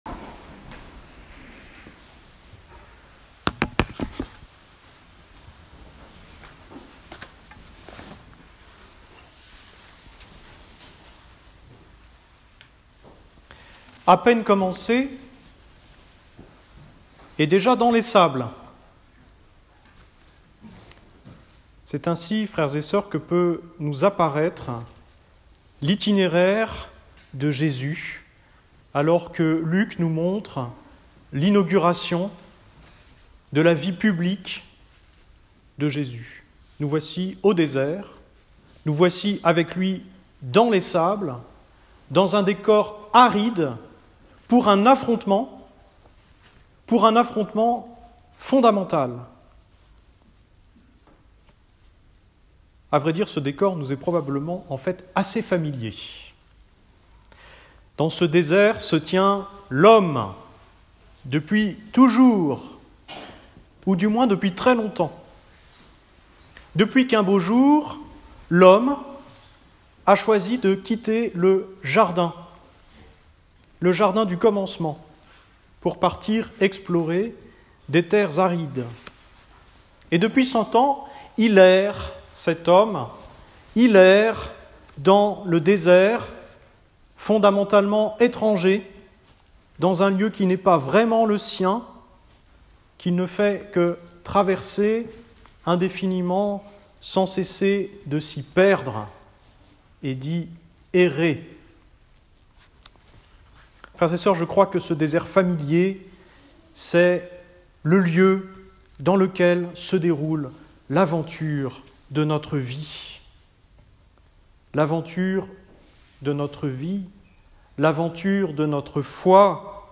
Enregistrement : l'homélie